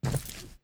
Footstep_Concrete 08.wav